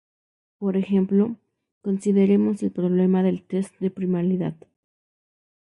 Pronounced as (IPA) /pɾoˈblema/